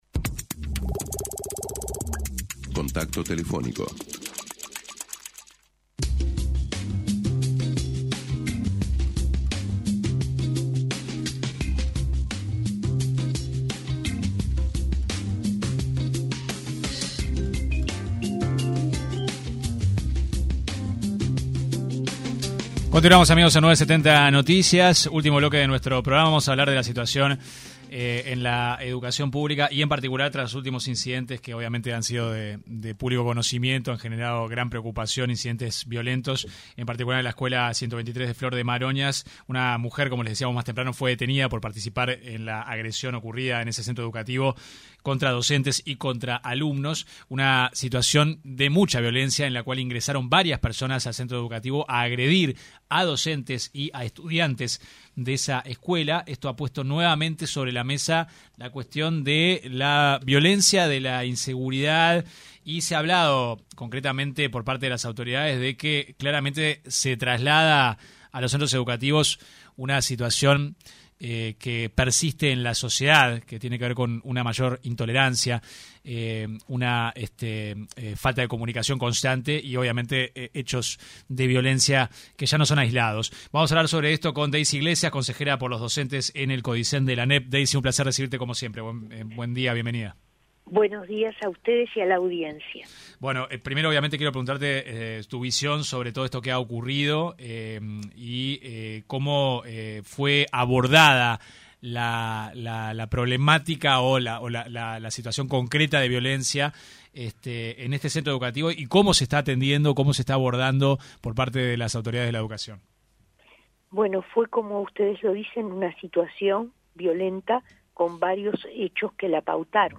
La consejera por los docentes del Codicen de la Anep, Daysi Iglesias, se refirió en diálogo con 970 Noticias, a la violencia en los centros de estudio, particularmente el último caso, ocurrido en la escuela 123 de Jardines del Hipódromo, donde una madre agredió a docentes, escolares y padres.